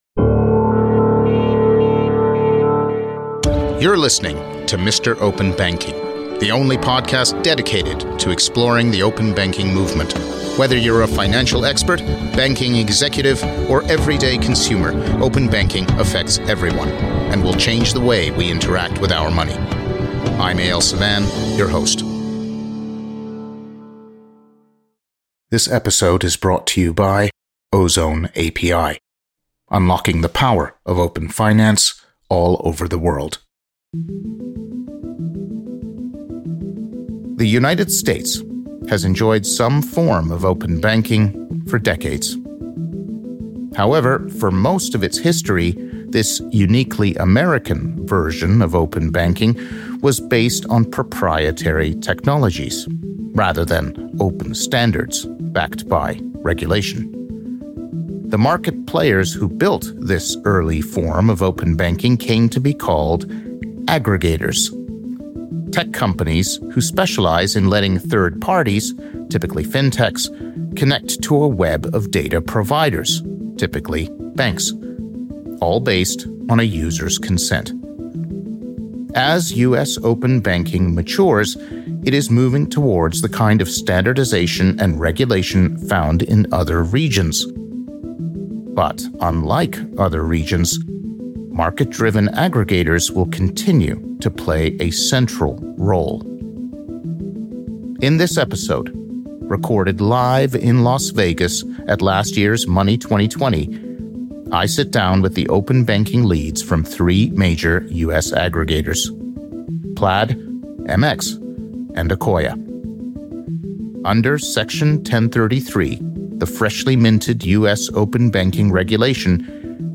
Recorded live at Money 20/20, 2024 in Las Vegas, Nevada